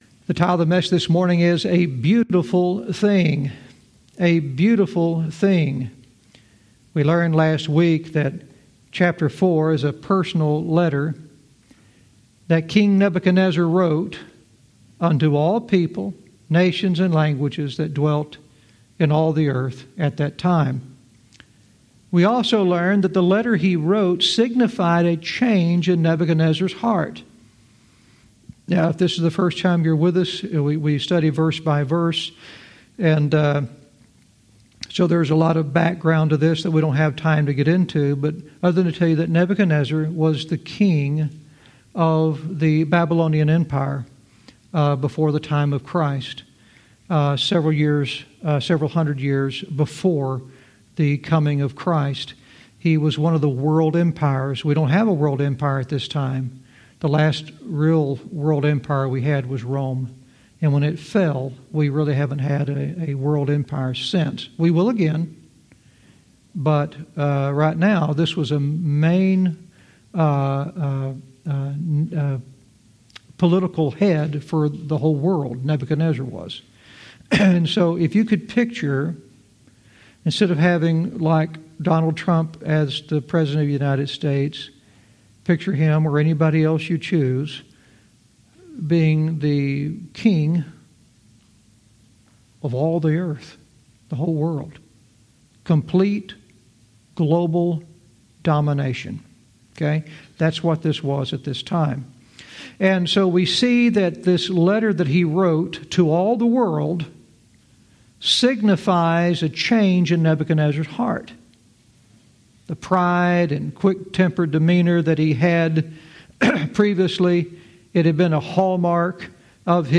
Verse by verse teaching - Daniel 4:2-3 "A Beautiful Thing"